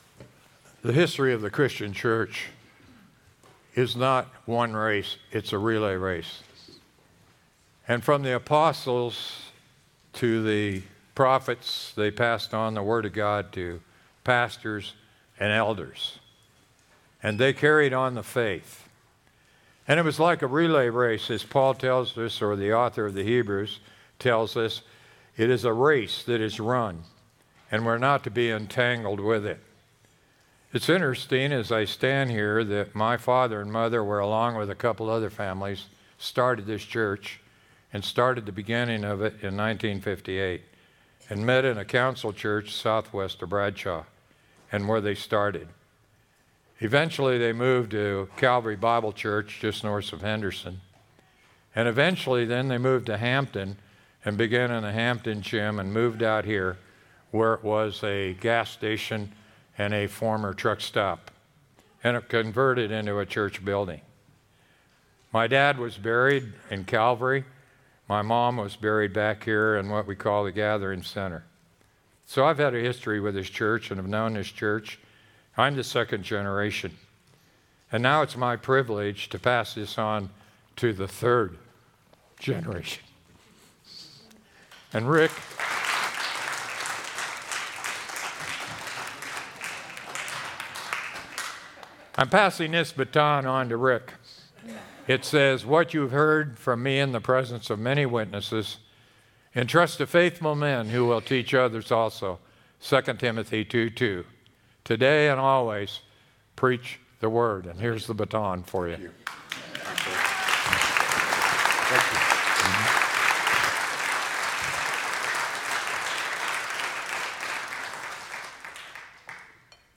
sermon-10-6-24.mp3